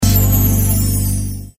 Тембр перкуссии для баллад
Как называется этот металл ударный инструмент. Для баллад хорошо подходит, в интро,в переходы...